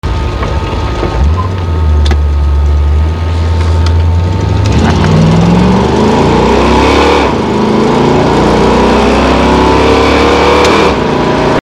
It quieted down a little with the mufflers located out back near the rear axle.
It has a really mean sound when you hit 4500 rpms, which I can only describe a changing from a low growling roar to a cross between a panther roar and fingernails being scraped against a chalkboard...It kinda screams and hisses and sounds REALLY MEAN!
Super 44s running in the car...
super44installed.mp3